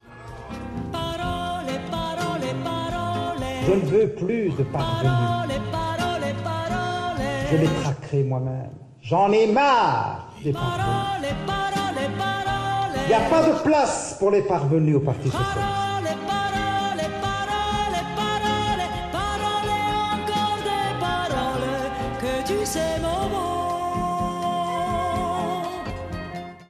un nouveau remix de la chanson